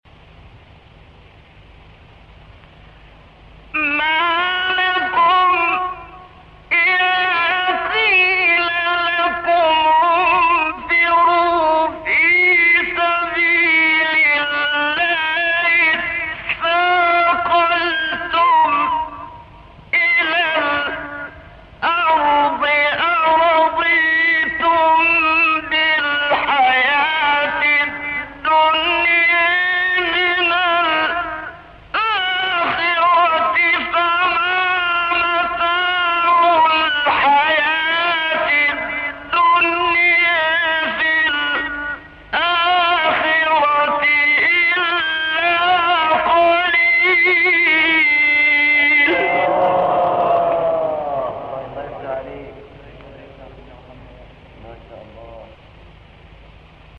گروه شبکه اجتماعی: مقاطعی صوتی از تلاوت قاریان برجسته مصری را می‌شنوید.
مقطعی از محمد صدیق منشاوی در مقام صبا